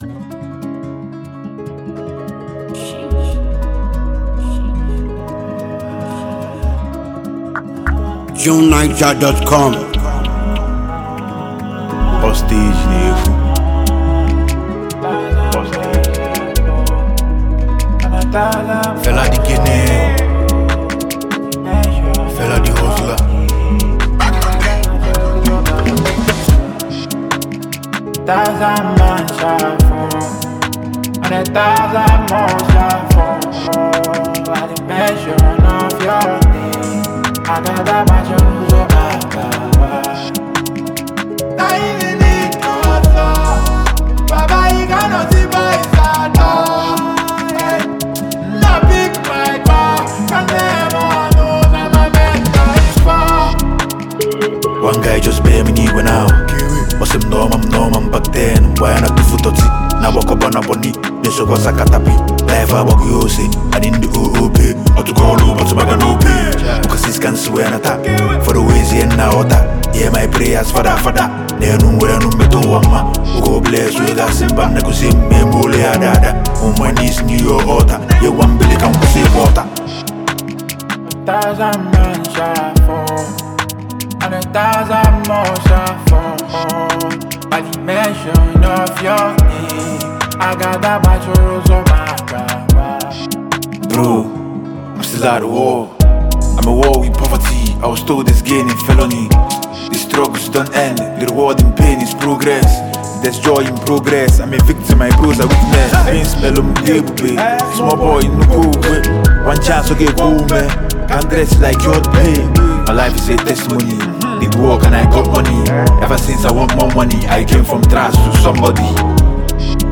exciting and lively new song